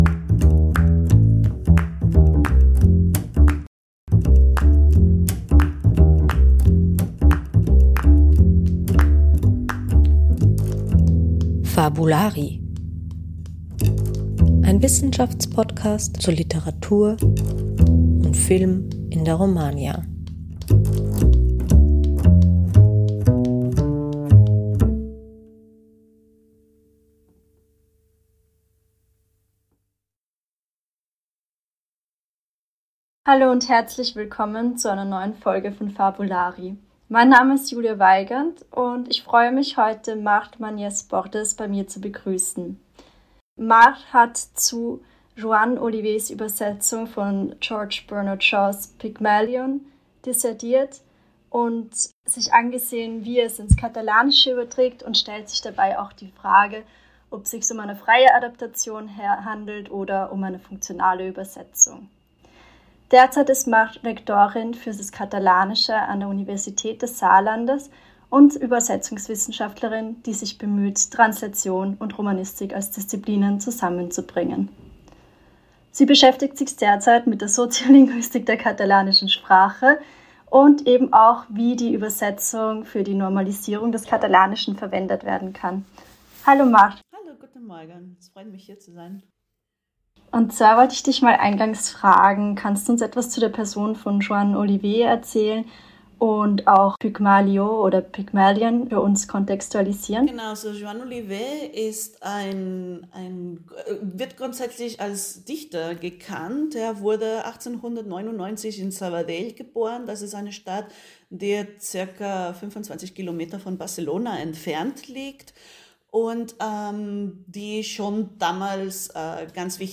Diskutiert wird insbesondere, wie diese Eingriffe zu bewerten sind: als freie Adaptation oder als funktionale Übersetzung. Interview